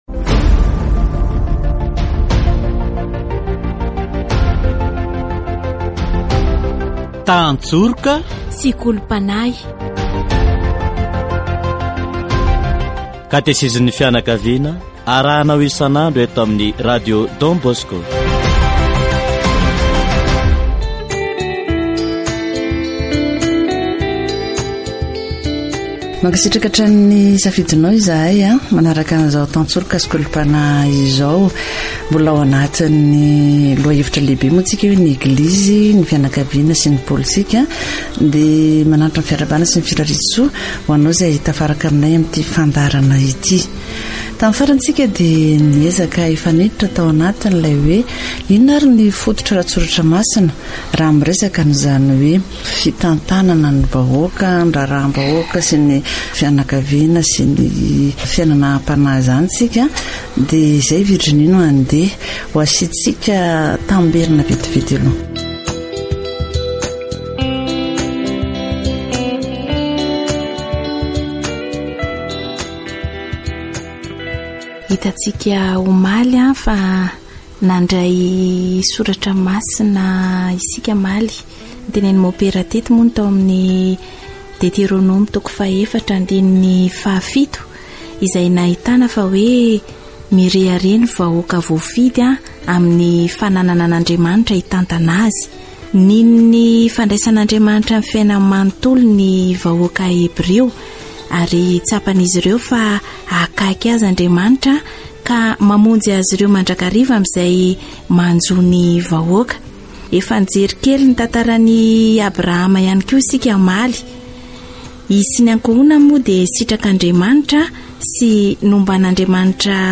Teo amin'ny akohonam-piainana kristiana voalohany : araka ny fampianaran'i Jesoa, tsy mifanohitra amin'izany ny nampianarin'ireo Apôstôly. Aoka ny olona rehetra samy hanaiky ny manam-pahefana satria tsy misy fahefana tsy avy amin'Andriamanitra fa voatendriny avokoa izay misy rehetra. Katesizy momba ny Eglizy, ny fianakaviana sy ny pôlitika